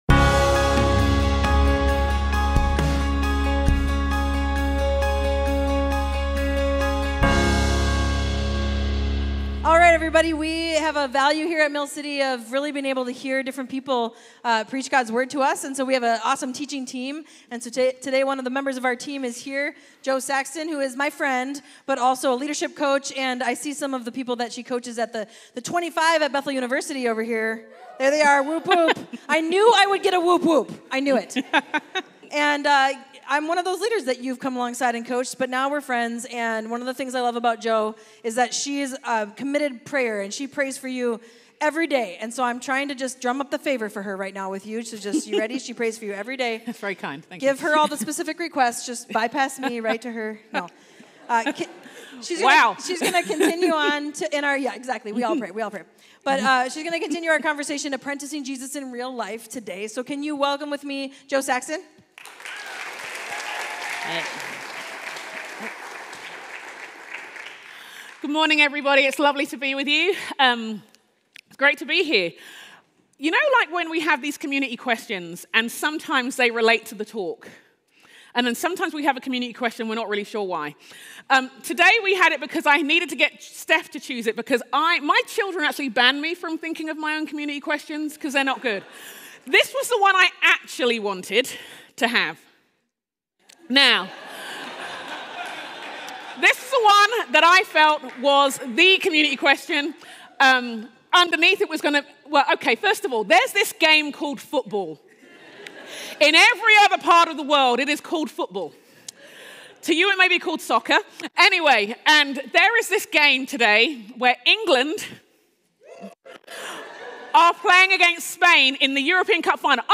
Mill City Church Sermons Apprenticing Jesus (IRL): Obedience Jul 15 2024 | 00:35:16 1x 00:00 / 00:35:16 Subscribe Share RSS Feed Share Link Embed